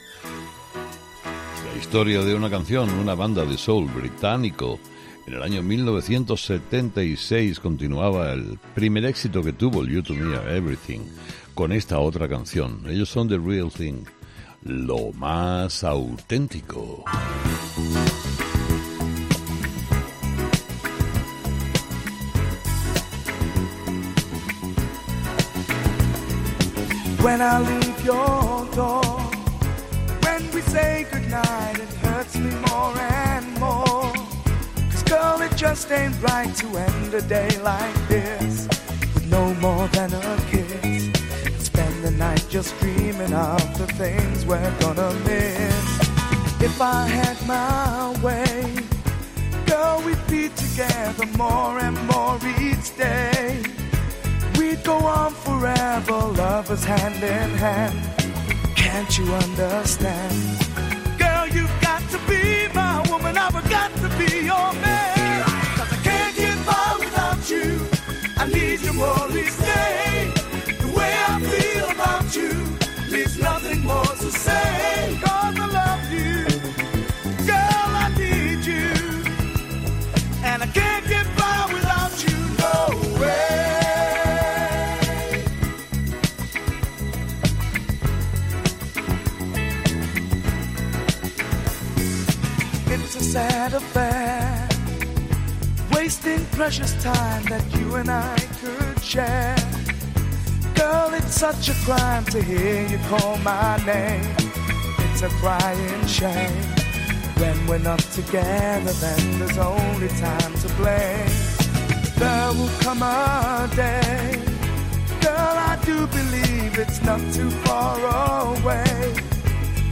Una banda de soul británico